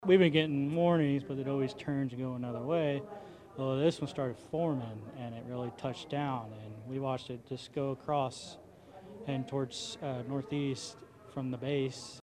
An Enid Resident tells FOX 25 in Oklahoma City